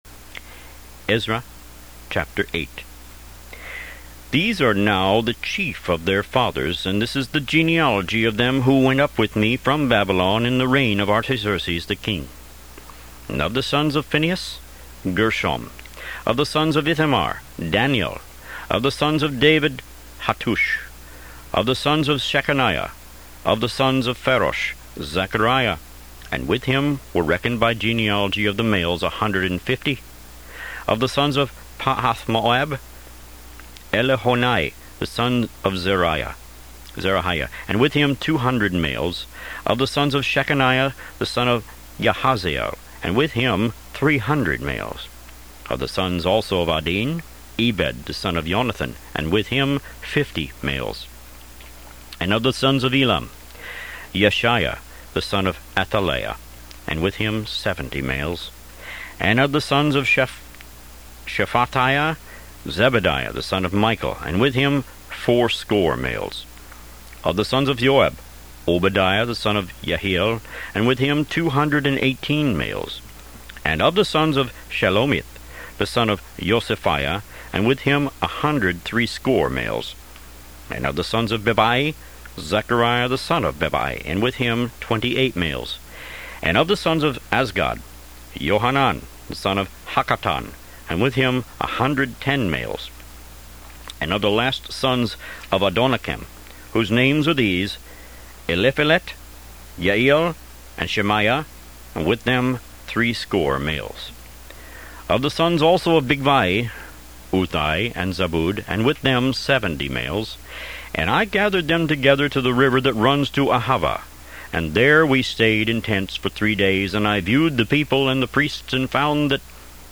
Root > BOOKS > Biblical (Books) > Audio Bibles > Tanakh - Jewish Bible - Audiobook > 15 Ezra